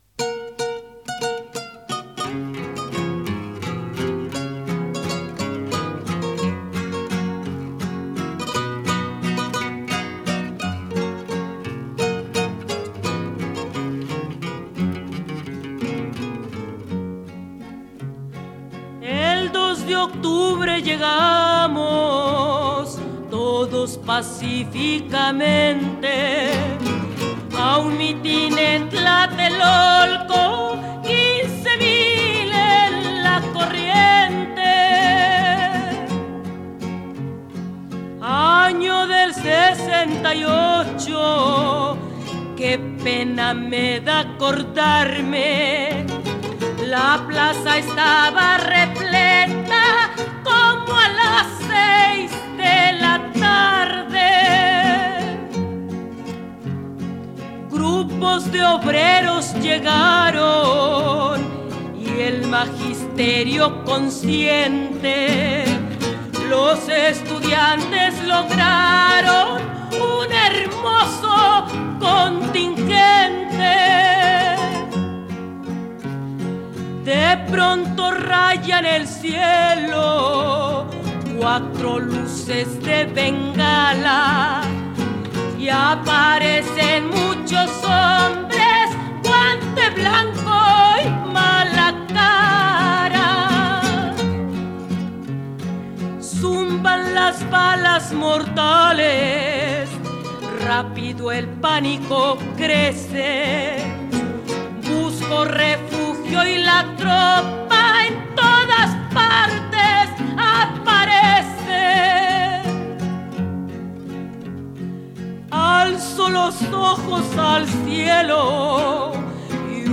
Hasta se le quiebra la voz cuando lo cuenta.